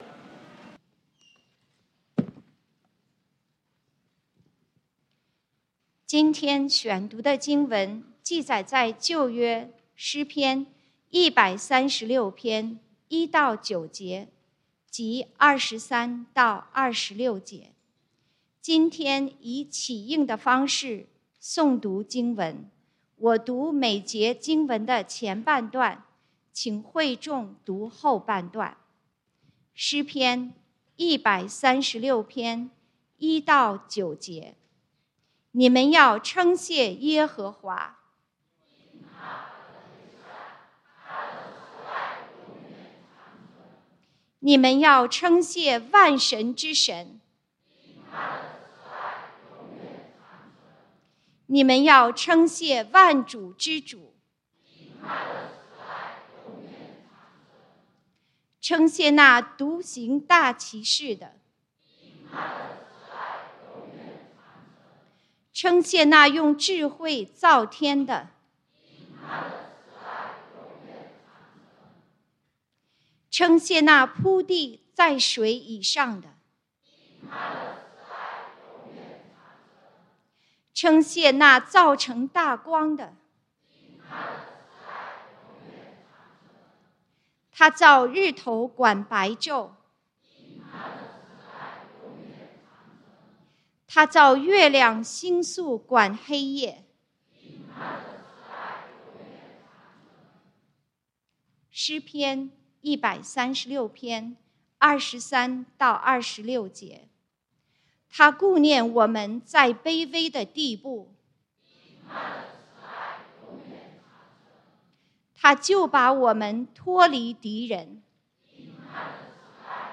感恩主日崇拜：感恩分享 (讀經經文：詩篇136:1-9, 23-26) | External Website | External Website